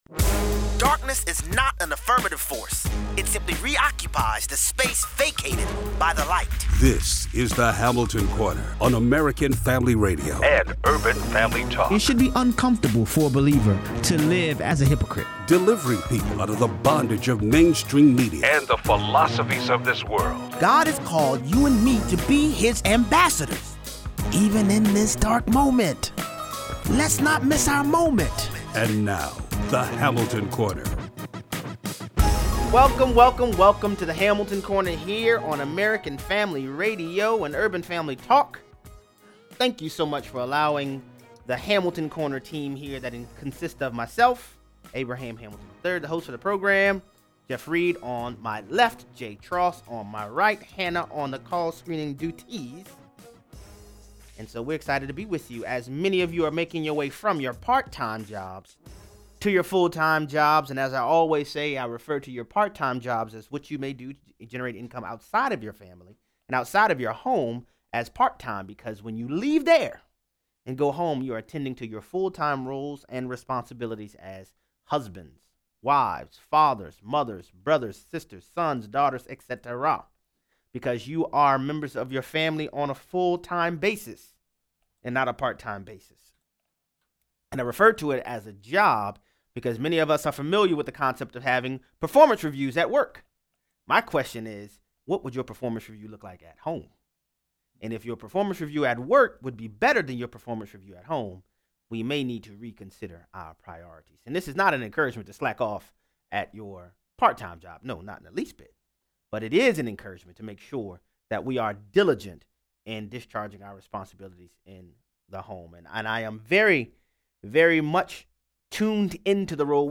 But, are Executive Orders the way we want the Federal Government run? 0:43 - 0:60: The US announces withdrawal from the UN Human Rights Council. Callers weigh in.